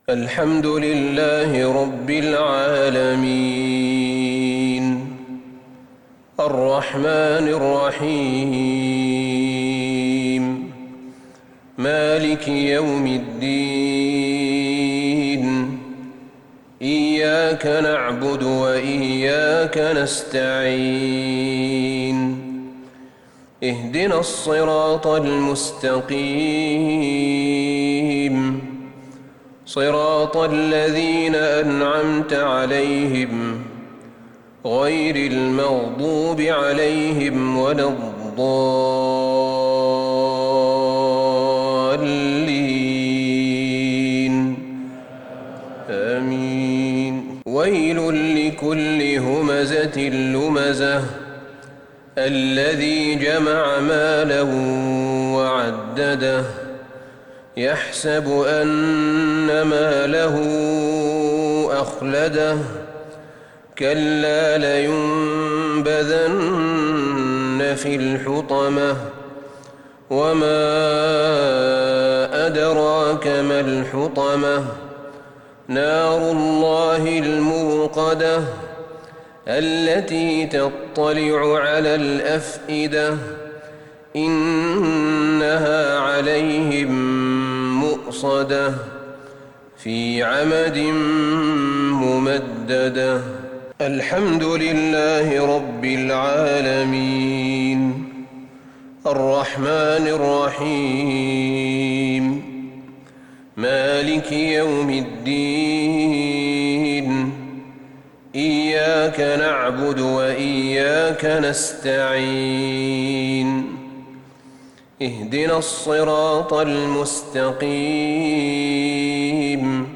صلاة المغرب للشيخ أحمد بن طالب حميد 2 ذو الحجة 1442 هـ
تِلَاوَات الْحَرَمَيْن .